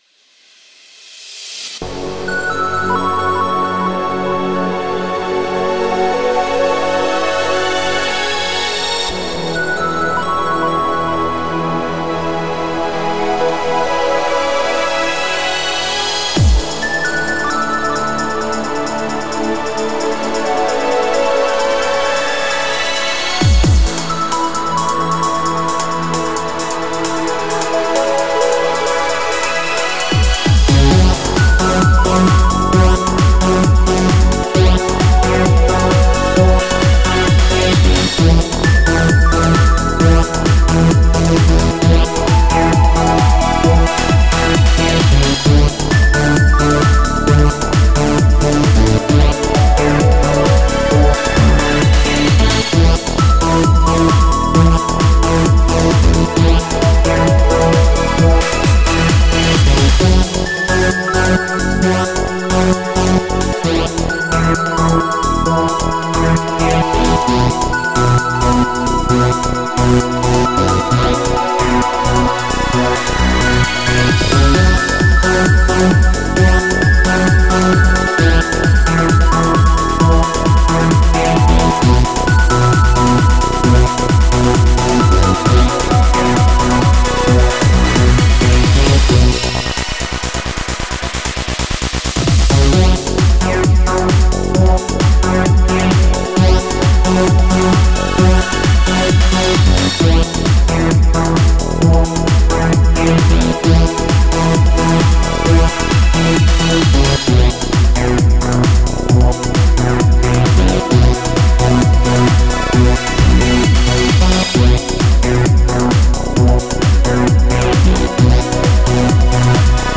Style: Eurodance
This upbeat dance track